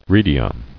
[re·di·a]